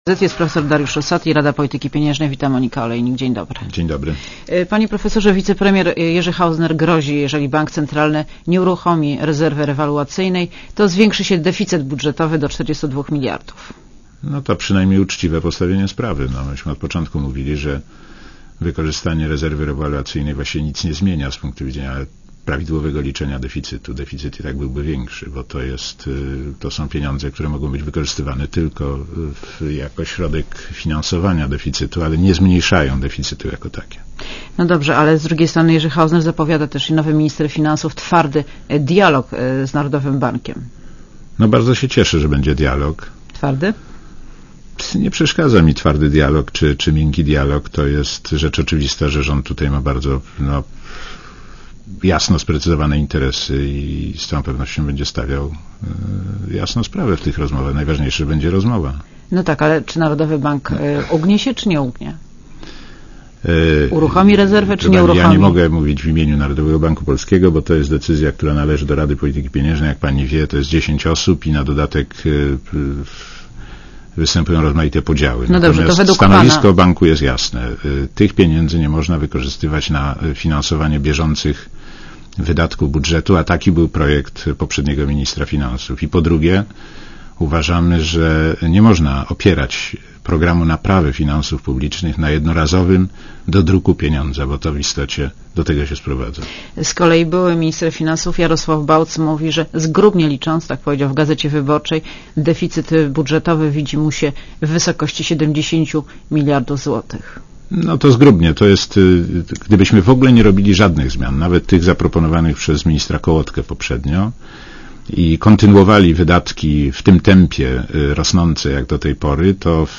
Monika Olejnik rozmawia z Dariuszem Rosatim - członkiem Rady Polityki Pieniężnej